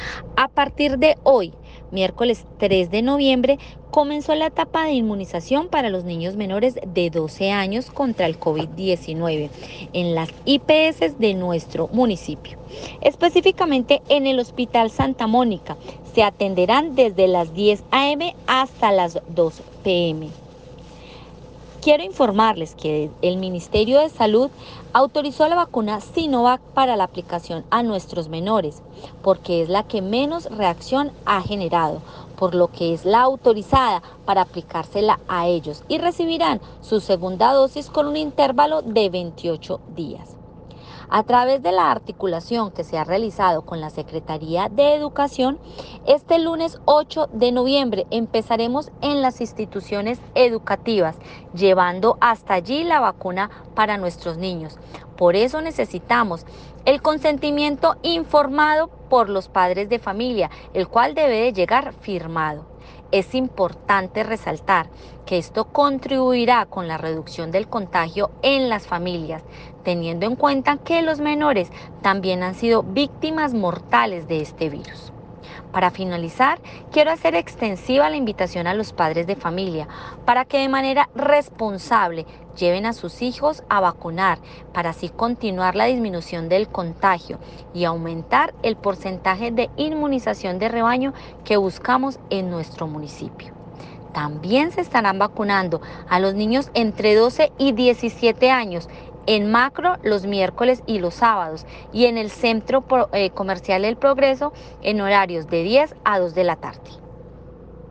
Así lo anunció la secretaria de Salud y Seguridad Social de Dosquebradas, Bibiana Romero Olarte, quien aseguró que, de acuerdo con la información del Ministerio de Salud, la vacuna Sinovac es la que menos reacción ha generado, por lo que es la autorizada para aplicar a los menores, quienes recibirán la segunda dosis en un intervalo de 28 días entre cada inyección.
Comunicado-744-Sec-Salud-Bibiana-Romero.mp3